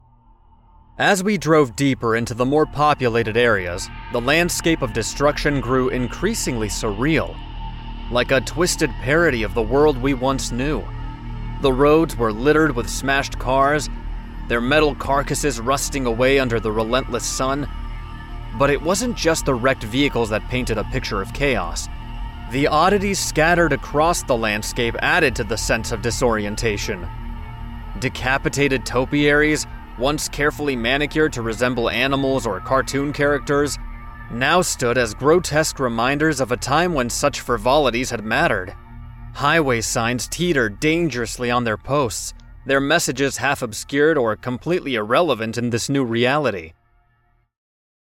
Narration
J'utilise un microphone RDE NT1 avec un filtre anti-pop et une interface Focusrite Scarlett Solo dans une cabine vocale Voctent insonorisée et Audacity pour l'enregistrement et le montage.